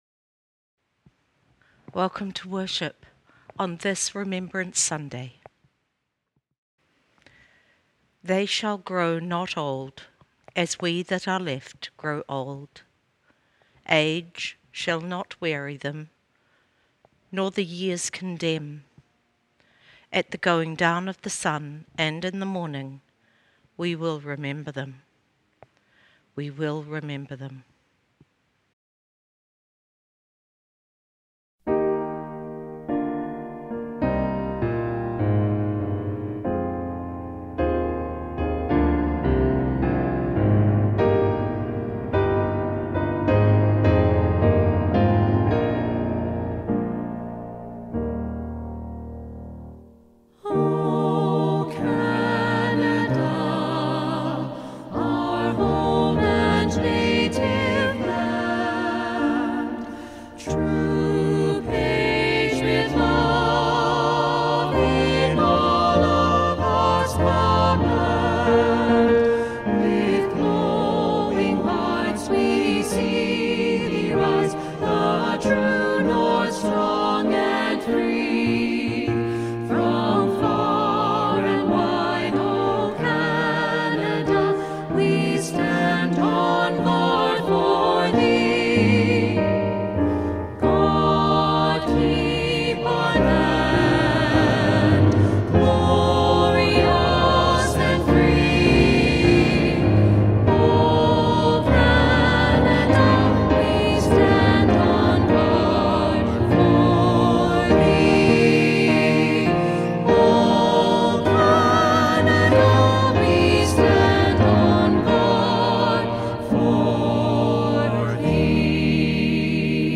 Welcome to this time of worship.